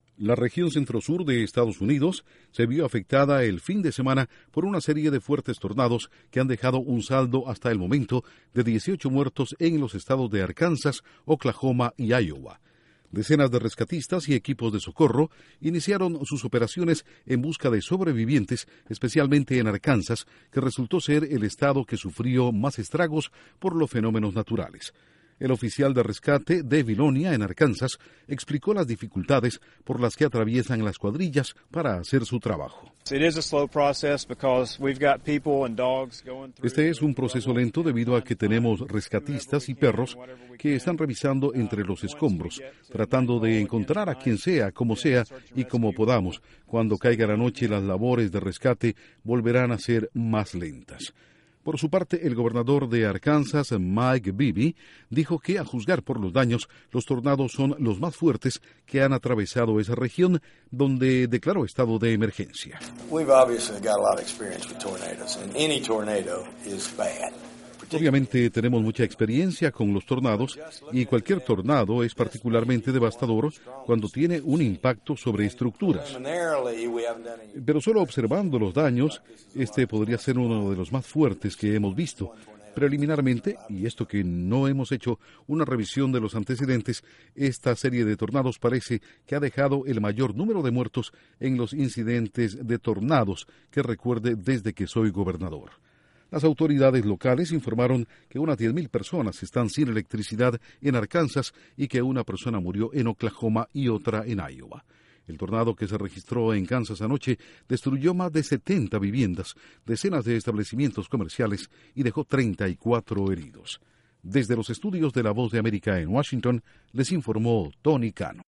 Tres estados de la región centro sur de Estados Unidos inician labores de rescate debido a tornados que han dejado muertos, heridos, y destrucción. Informa desde la Voz de América en Washington